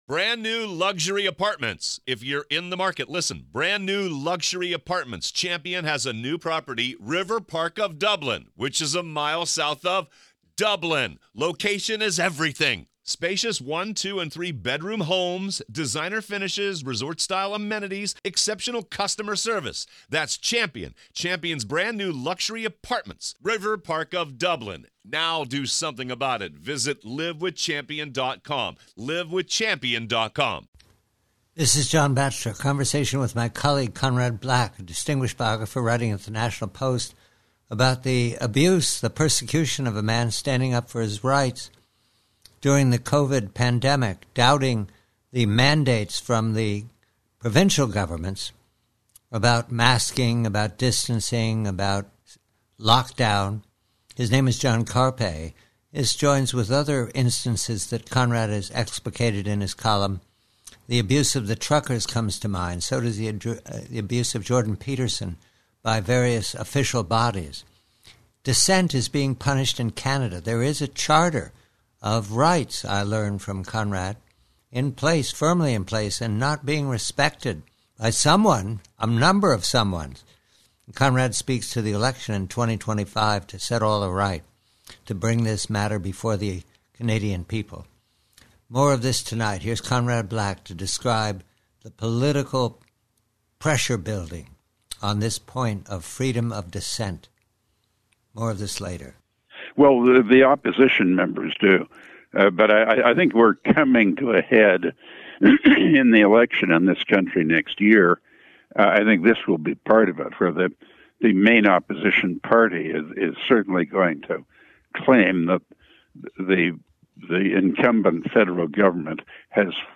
PREVIEW: CANADA: DISSENT: Conversation with colleague Conrad Black re freedom of speech in Canada after several puzzling examples of hectoring and punishing outspoken dissent.